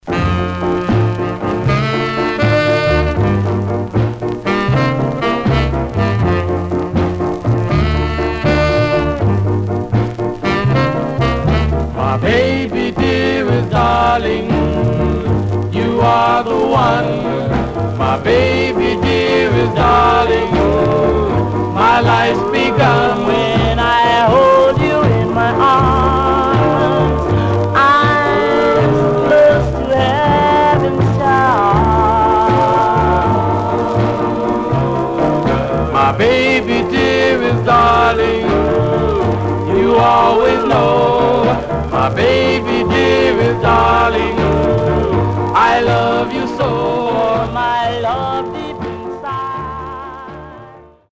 Mono
Male Black Groups